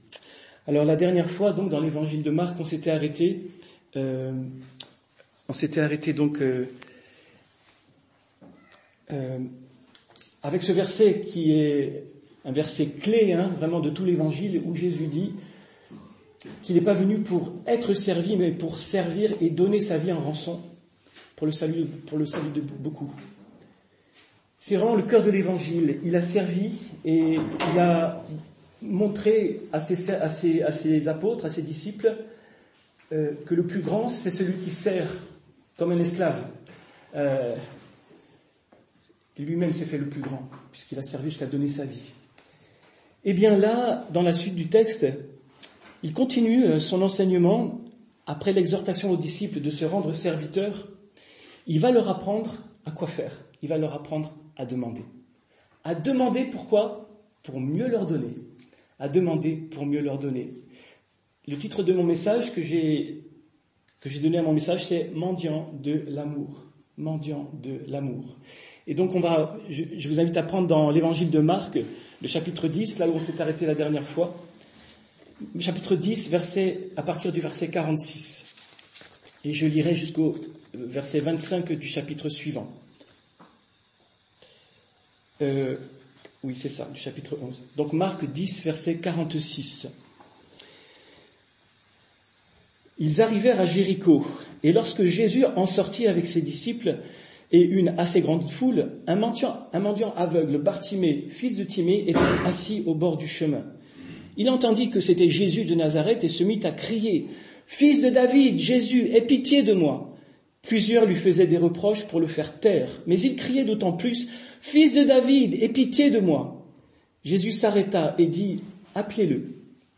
Culte du dimanche 6 octobre 2024 - EPEF